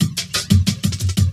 17 LOOP09 -R.wav